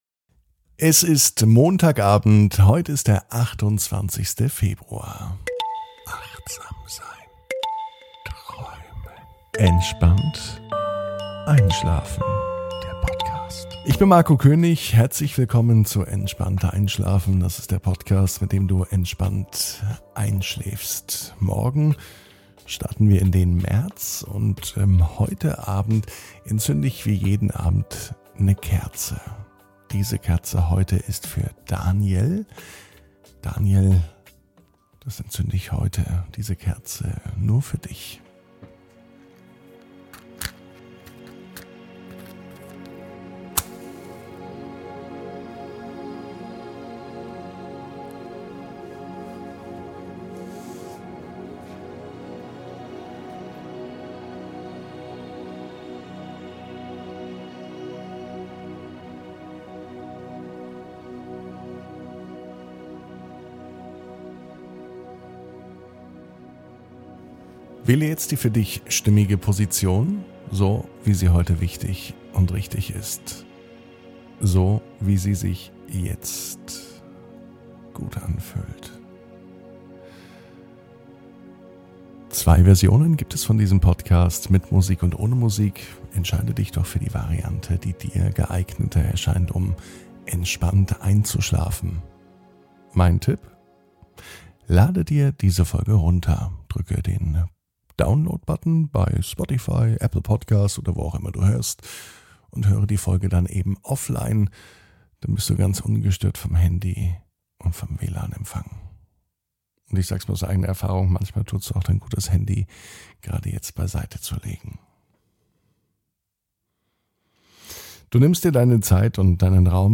(ohne Musik) Entspannt einschlafen am Montag, 28.02.22 ~ Entspannt einschlafen - Meditation & Achtsamkeit für die Nacht Podcast